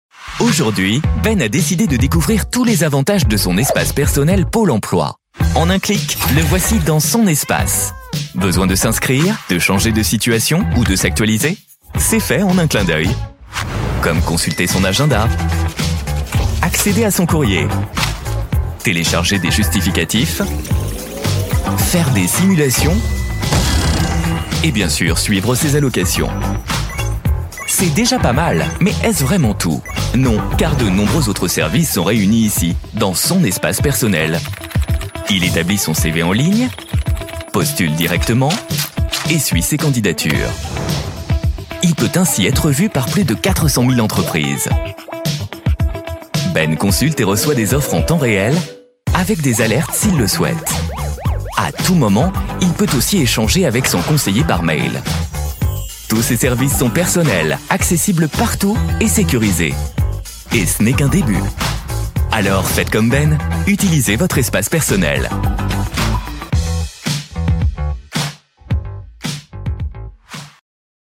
PÔLE EMPLOI didactique - Comédien voix off
Genre : voix off.